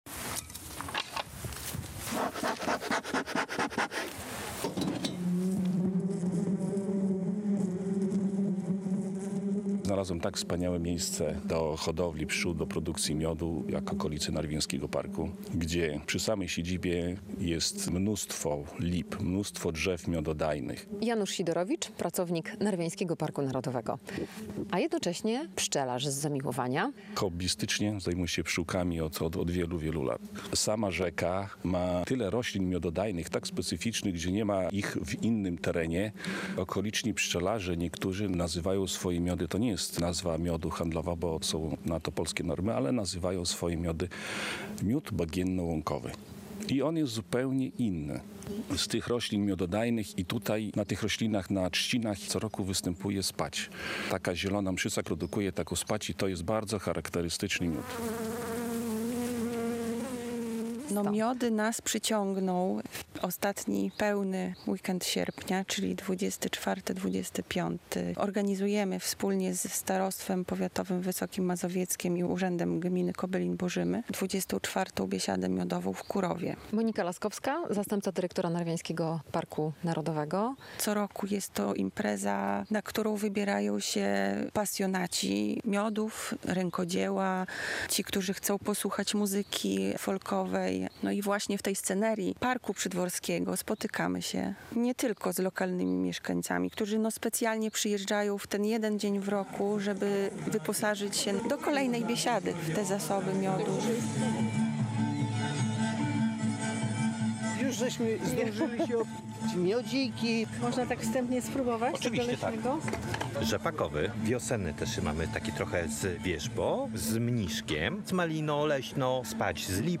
Narwiański Park Narodowy słynie z miodów i kruszewskich ogórków - relacja